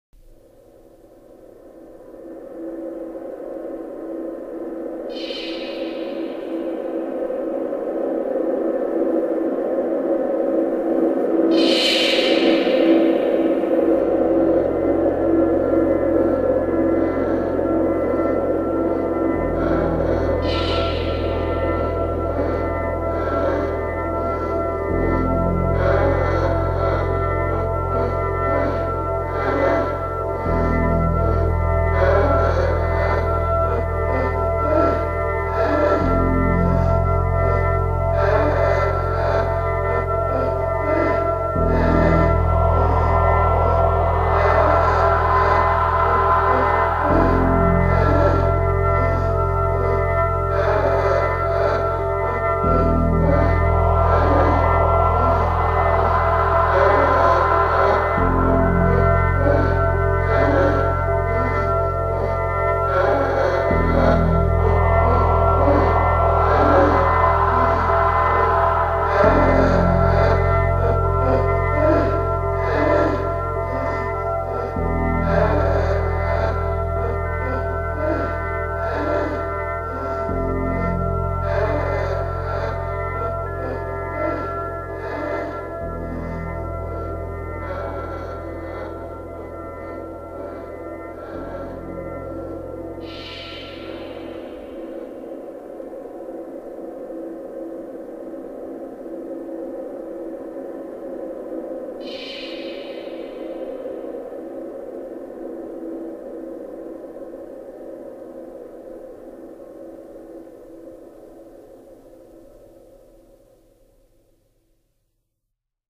• Genre: Experimental / Ambient / Drone